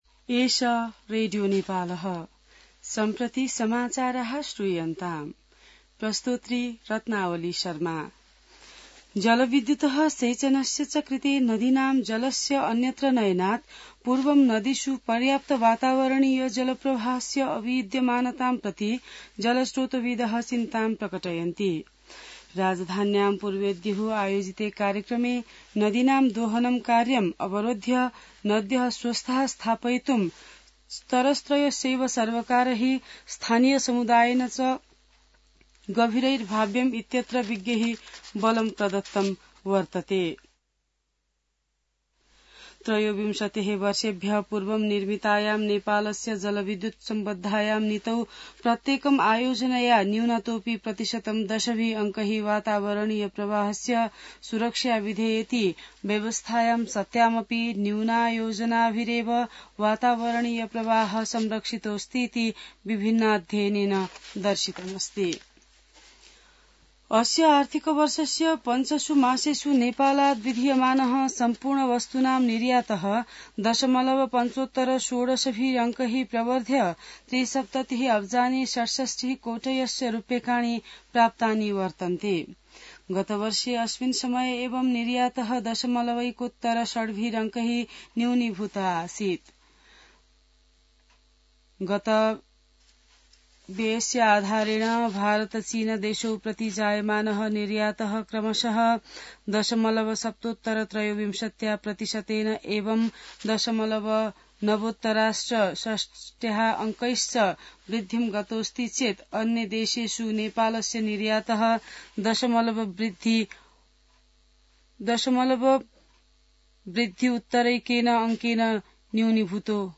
संस्कृत समाचार : २९ पुष , २०८१